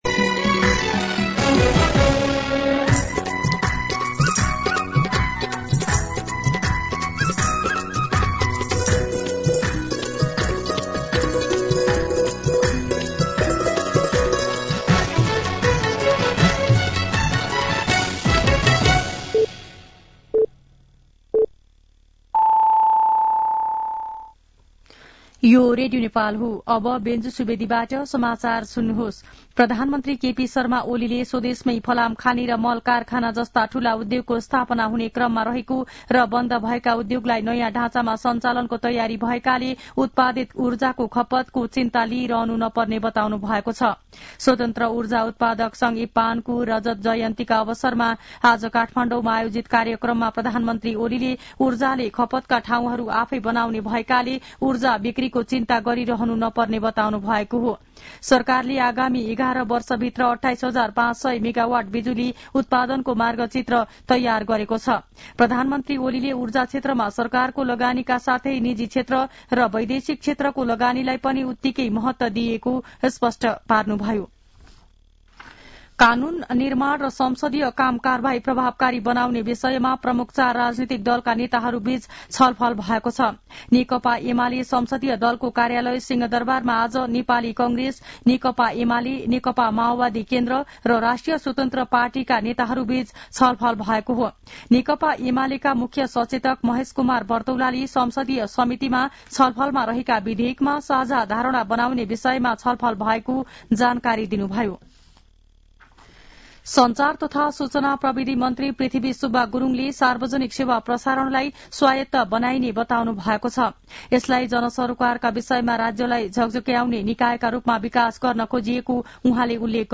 दिउँसो ४ बजेको नेपाली समाचार : ५ माघ , २०८१
4-pm-Nepali-News-10-4.mp3